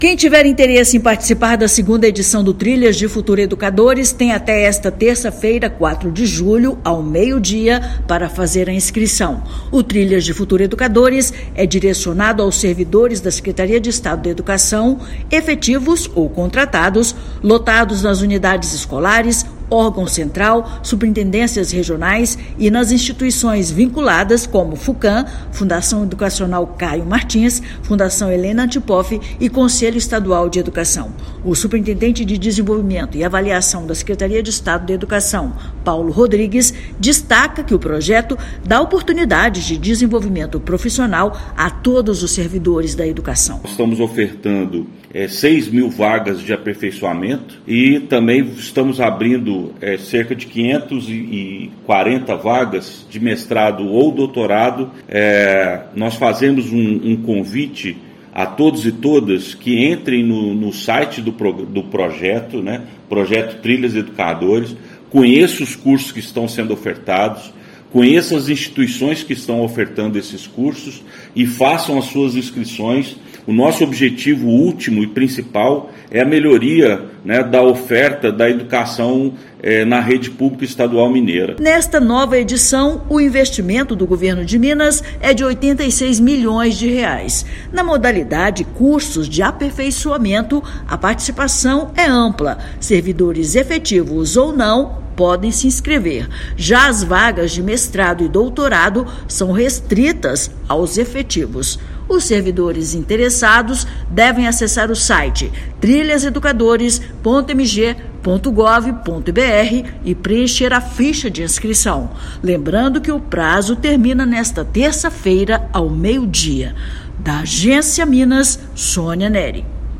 O programa oferece mais de 6 mil vagas para cursos de aperfeiçoamento e mais de 500 vagas de mestrado e doutorado. Ouça matéria de rádio.